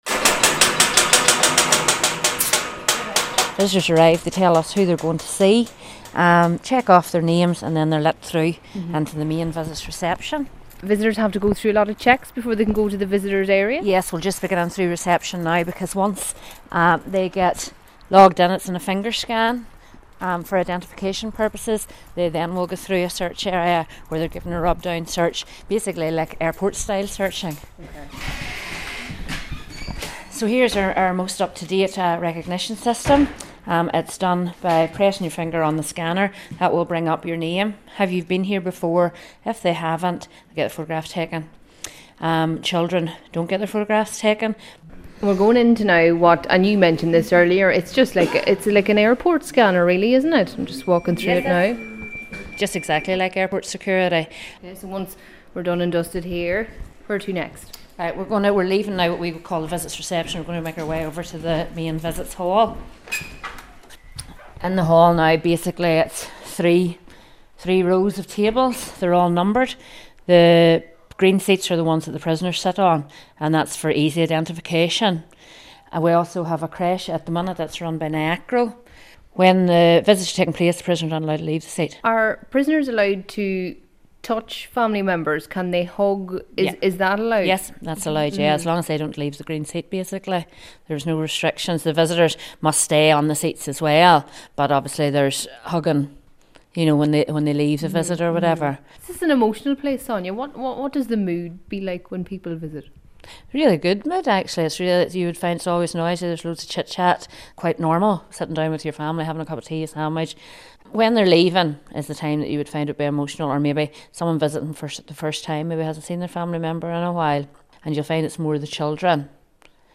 LISTEN: Behind-the-scenes at the visitors hall at Magilligan Prison